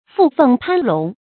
附鳳攀龍 注音： ㄈㄨˋ ㄈㄥˋ ㄆㄢ ㄌㄨㄙˊ 讀音讀法： 意思解釋： 指巴結投靠有權勢的人以獲取富貴。